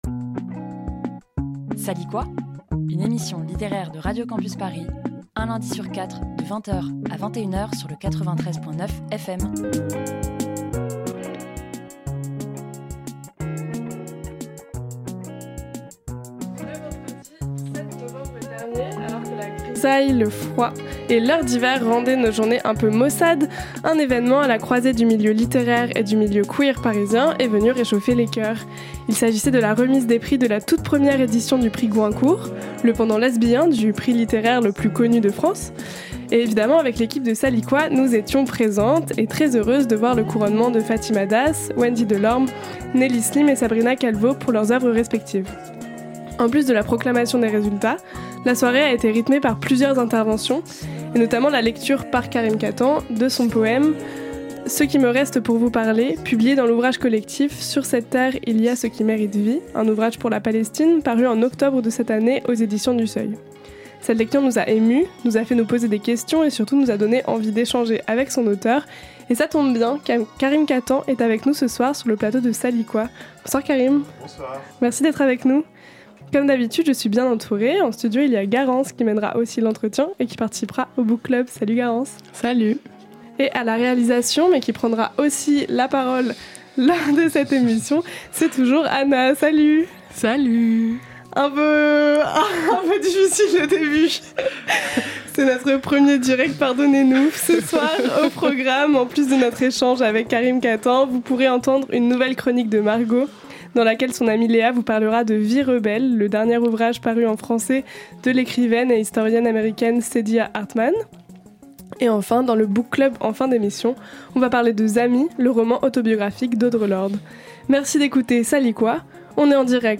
Dans ce premier direct acrobatique, nous recevons l'auteur palestinien et français qui, après un recueil de nouvelles et deux romans, a publié en avril 2025 un recueil de poésie, Hortus Conclusus.